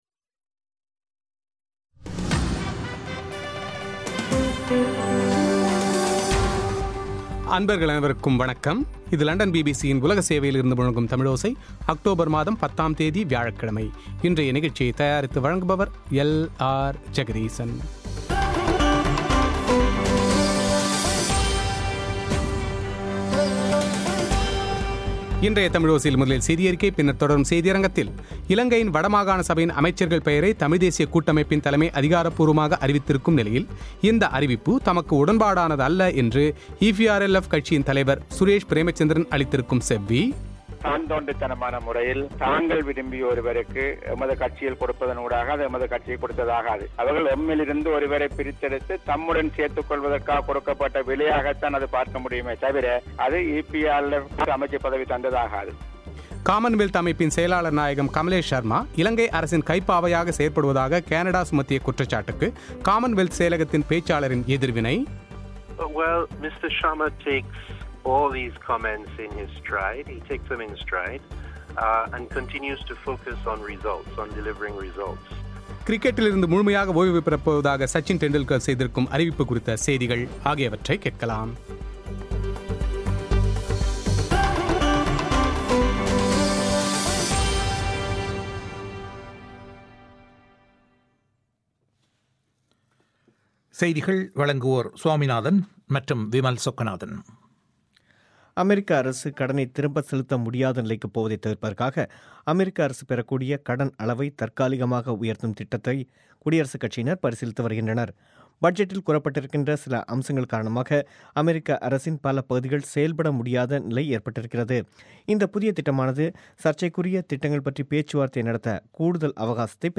இலங்கையின் வடமாகாணசபையின் அமைச்சர்கள் பெயரை தமிழ்தேசிய கூட்டமைப்பின் தலைமை அறிவித்திருக்கும் நிலையில், இந்த அறிவிப்பு தமக்கு உடன்பாடானதல்ல என்று ஈபிஆர்எல்எப் கட்சியின் தலைவர் சுரேஷ் பிரேமச்சந்திரன், பிளாட் அமைப்பின் சித்தார்த்தன் ஆகியோர் பகிரங்கமாக எதிர்த்திருப்பது குறித்து அவர்களின் செவ்விகள்;